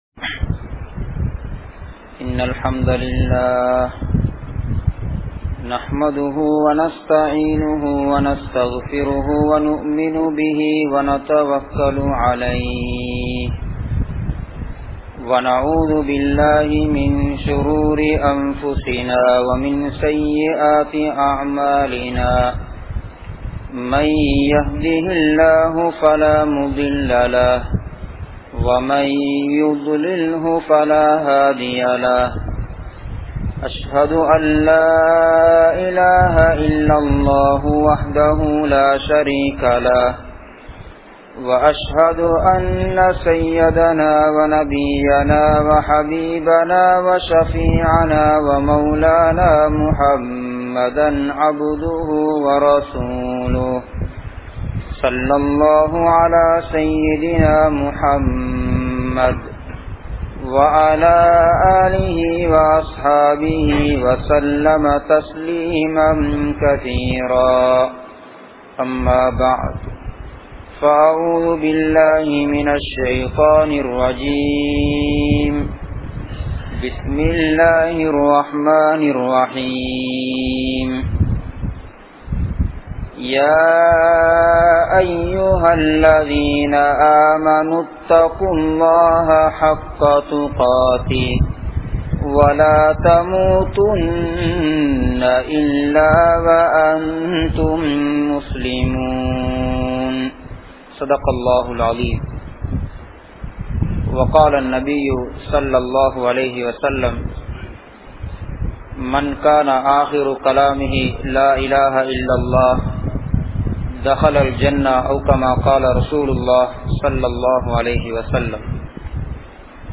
Tholuhai Illaathavanin Mudivu (தொழுகை இல்லாதவனின் முடிவு) | Audio Bayans | All Ceylon Muslim Youth Community | Addalaichenai
Galle, Kanampittya Masjithun Noor Jumua Masjith